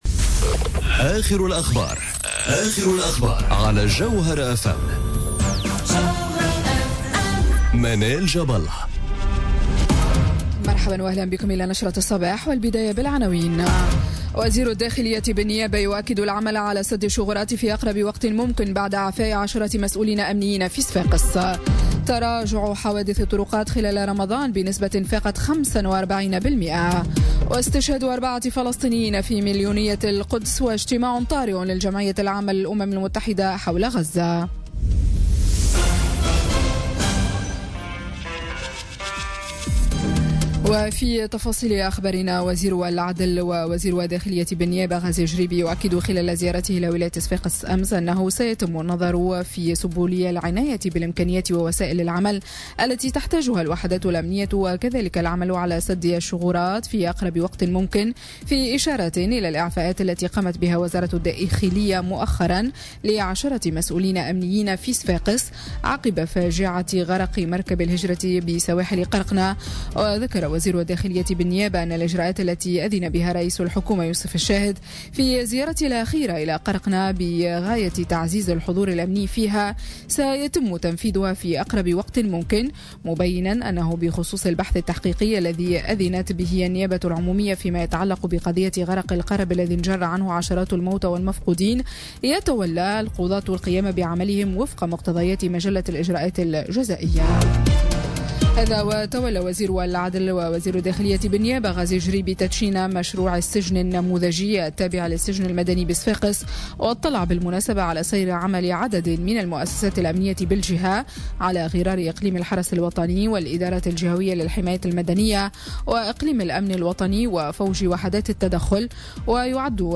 نشرة أخبار السابعة صباحا ليوم السبت 09 جوان 2018